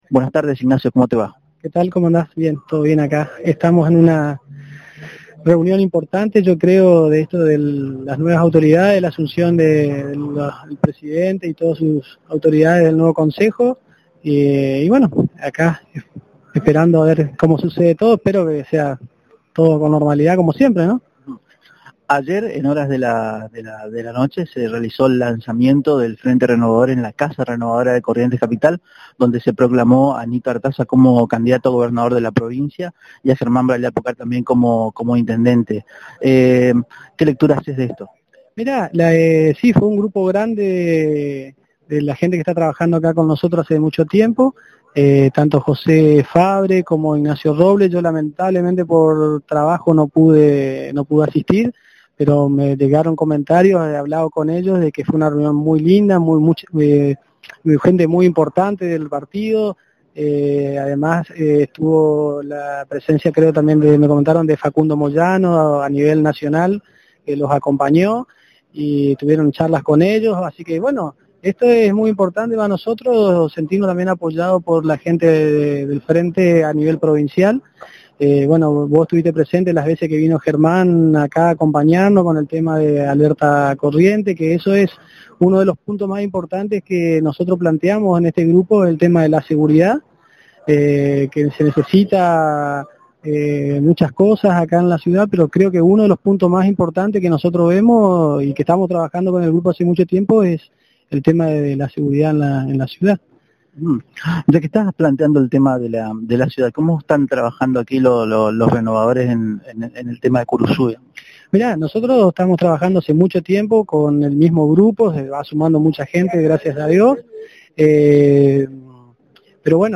El miércoles por la tarde en la sesión de asunción y jura de la nueva mesa directiva del Concejo, se lo vio presente en el recinto y accedió a una entrevista con éste medio.